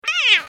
جلوه های صوتی
دانلود صدای کیتی عصبانی از ساعد نیوز با لینک مستقیم و کیفیت بالا
برچسب: دانلود آهنگ های افکت صوتی انسان و موجودات زنده دانلود آلبوم صدای انواع گربه از افکت صوتی انسان و موجودات زنده